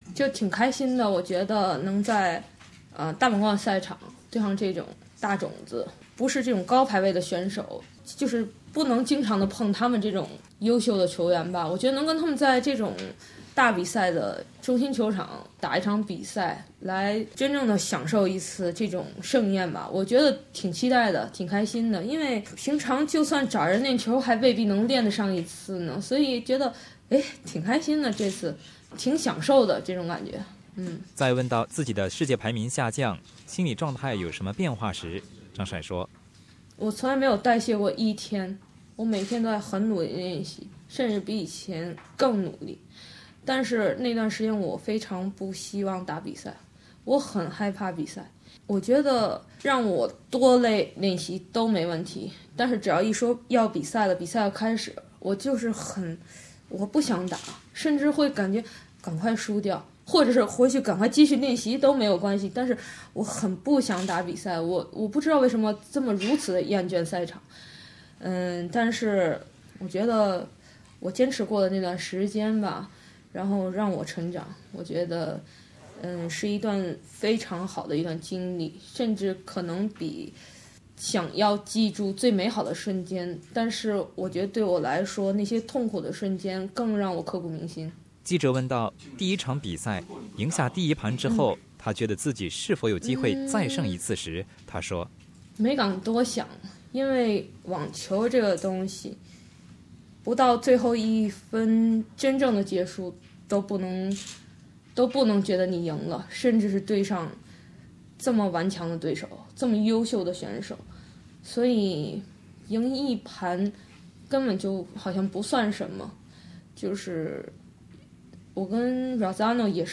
2016澳网公开赛，中国选手张帅在女单第一轮爆出最大冷门，2：0完胜世界排名第2的名将哈勒普，第15次冲击大满贯终于取得了大满贯正赛首胜。之后的媒体见面上，张帅谈到她的低落期，以及面对强大对手时的心理感受。